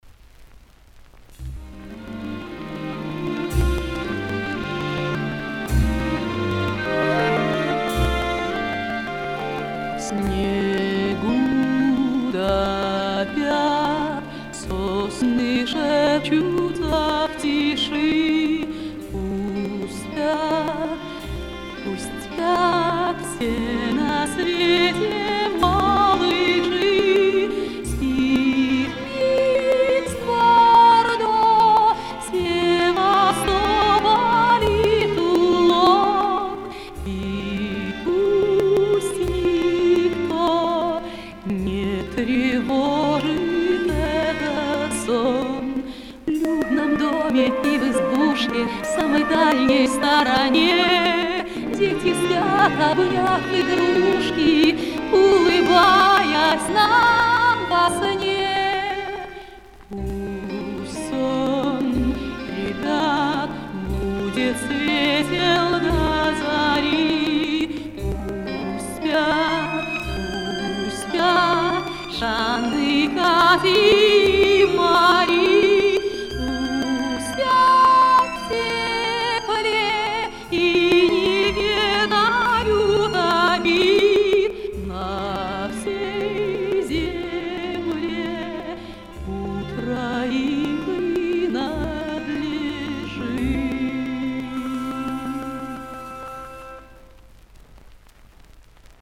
А звук какой-то "плавающий" в песне.